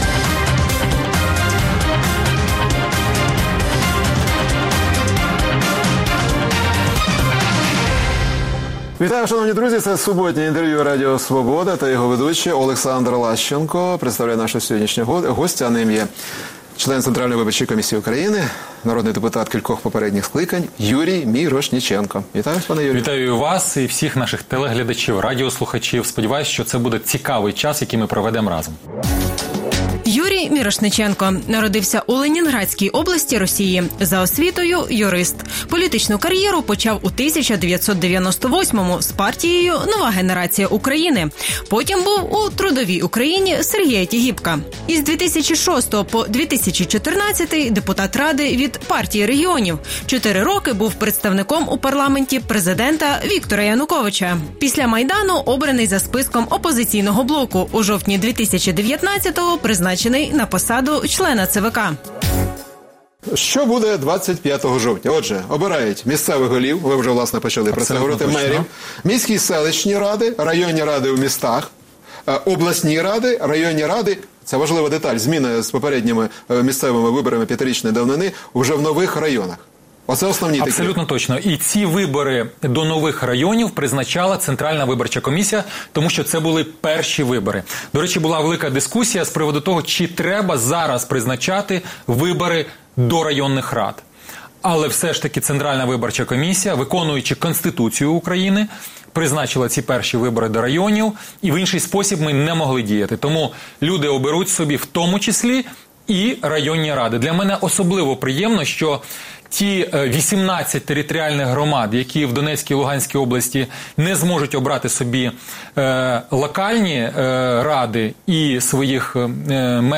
Суботнє інтерв’ю | Юрій Мірошниченко, член ЦВК, колишній народний депутат
Суботнє інтвер’ю - розмова про актуальні проблеми тижня. Гість відповідає, в першу чергу, на запитання друзів Радіо Свобода у Фейсбуці